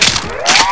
RiflePck.ogg